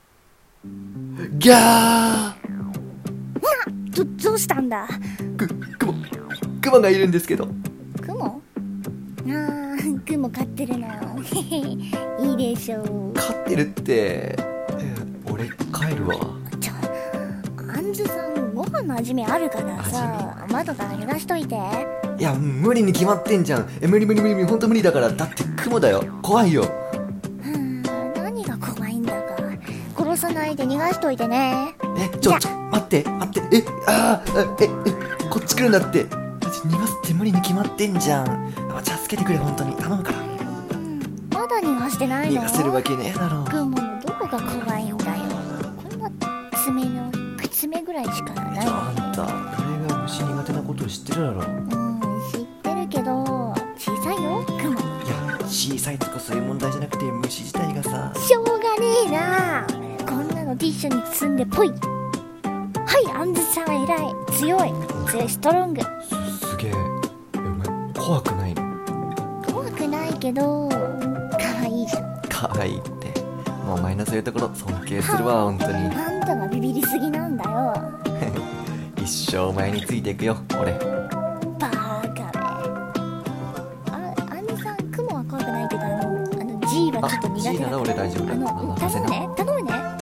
【音量注意！】「声劇台本」虫が怖い彼氏と強い彼女。